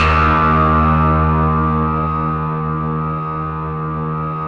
RESMET D#2-L.wav